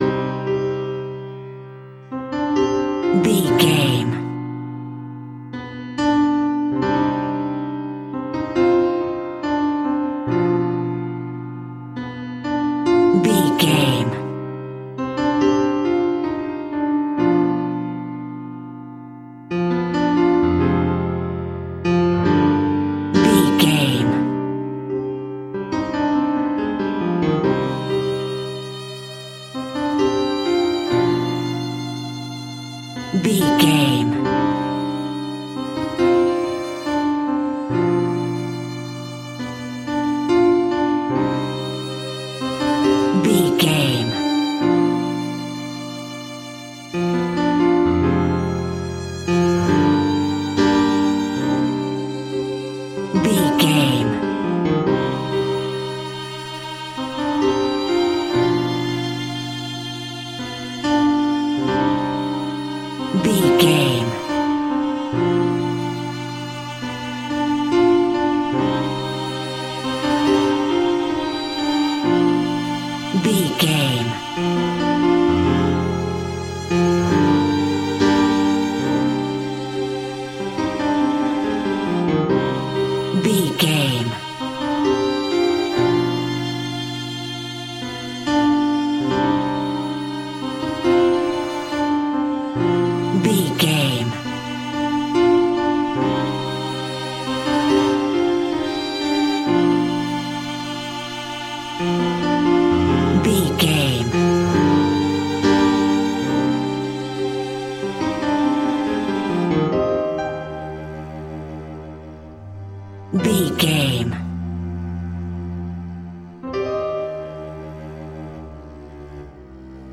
Aeolian/Minor
scary
tension
ominous
dark
suspense
eerie
piano
horror
synth
keyboards
ambience
pads